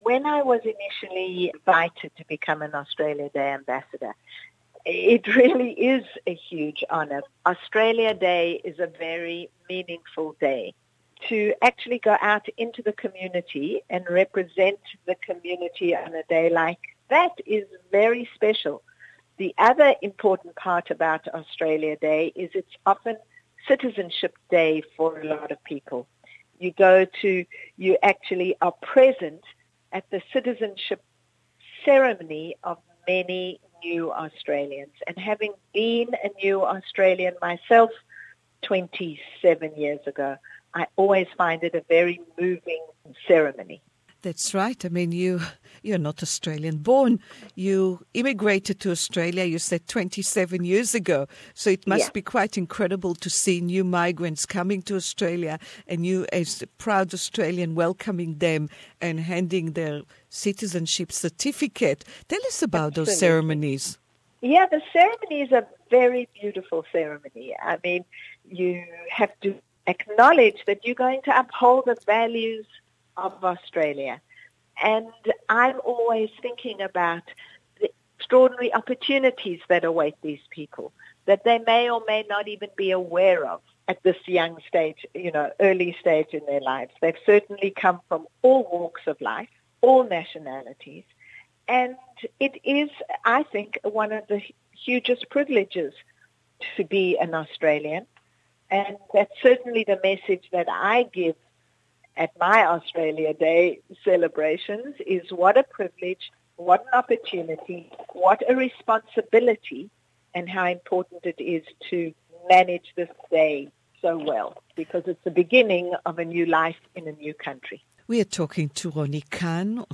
Ronni Kahn Australia Day Ambassador! interview in English